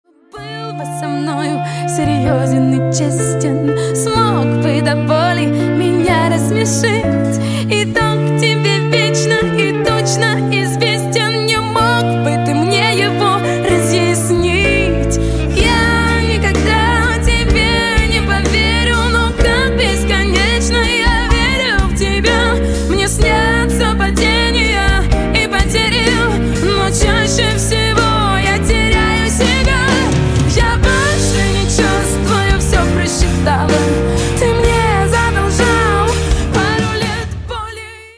• Качество: 128, Stereo
поп
женский вокал